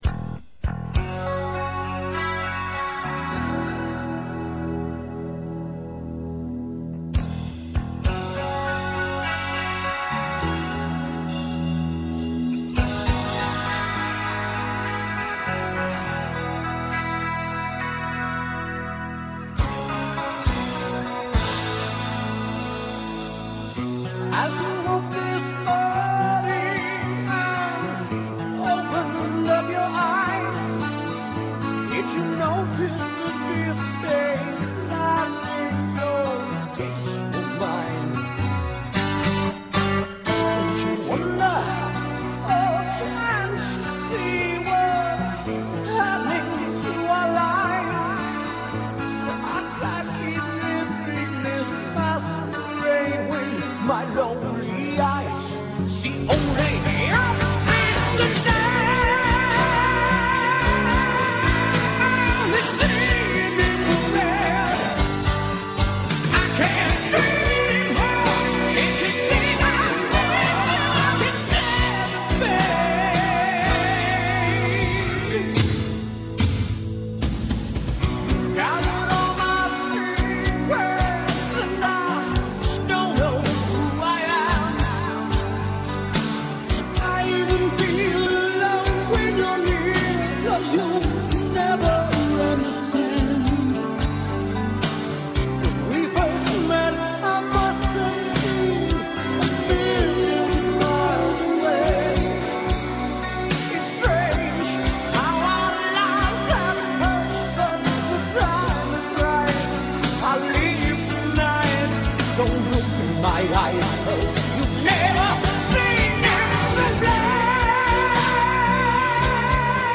Ein Akustik-Remix